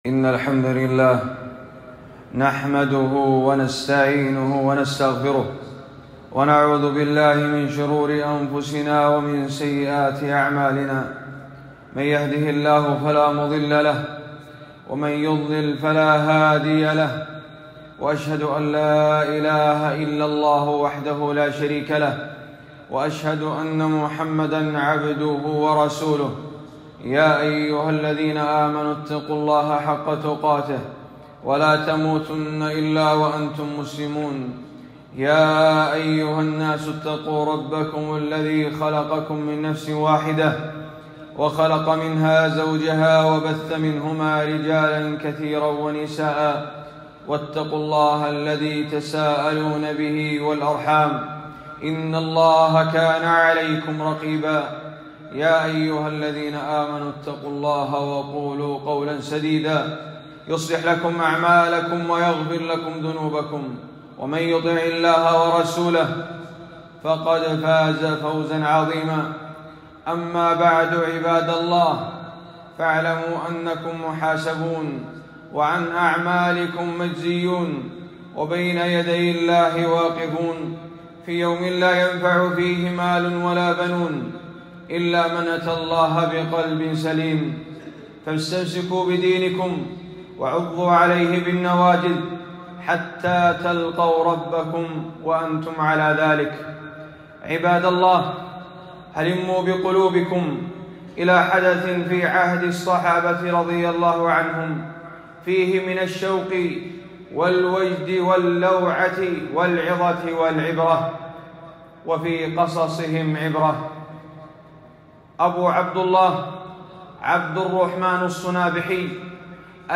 خطبة - هِجرة الصُنابحي فوائد وعِبَر